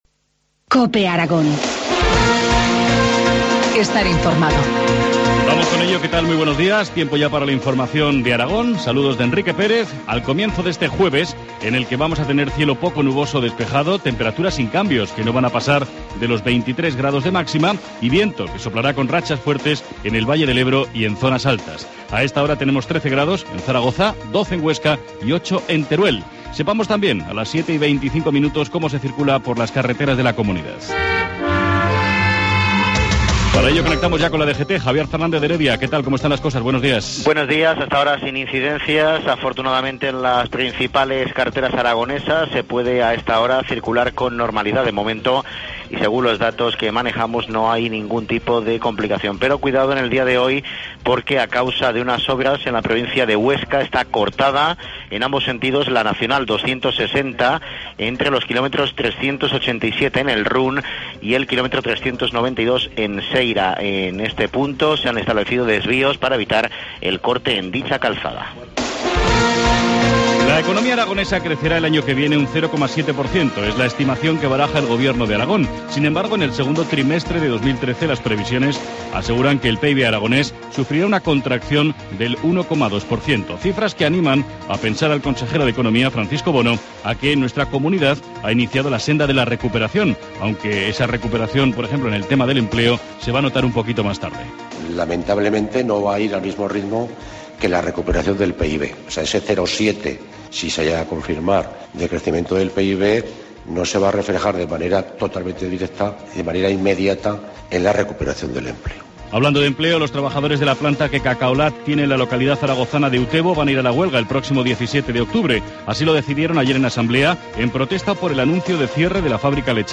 Informativo matinal, jueves 9 de octubre, 7.25 horas